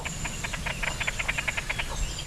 richiami ( 107 KB ) registrato a fine Aprile 2003: un poco musicale ticchettìo, che potrebbe essere legato all'inizio della "danza" nuziale di una coppia o comunque essere un forte richiamo tra i membri di una coppia.(Bibliografia [4]).
svassomaggiore.wav